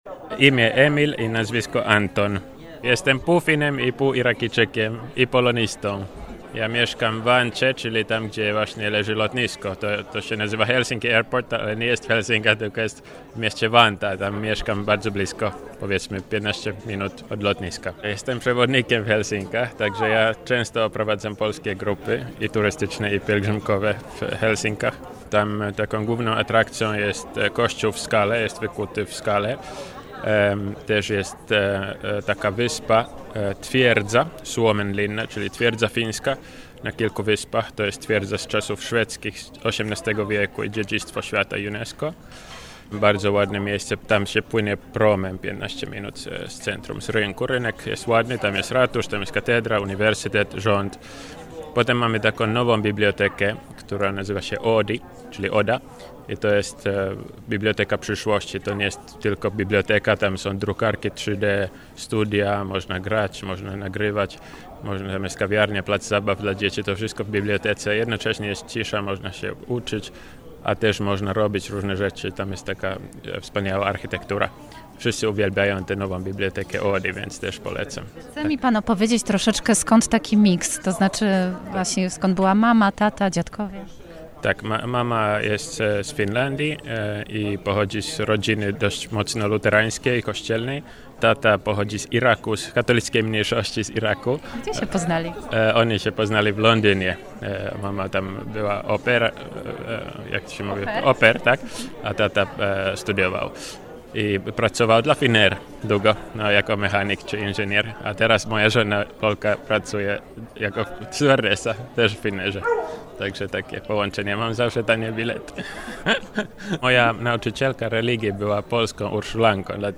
I tak zaczęła się rozmowa.